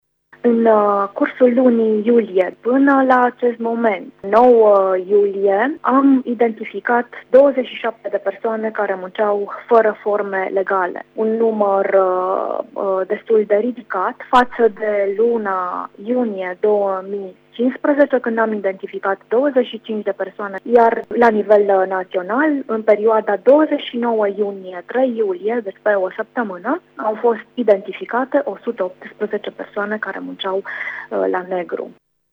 Totodată, a fost înaintată o sesizare către Parchetul de pe lângă Judecătoria Tîrgu-Mureş pentru angajarea a mai mult de 5 persoane fără încheierea unui contract individual de muncă, a declarat, pentru RTM, șefa Inspectoratului Teritorial de Muncă Mureș, Eva Man: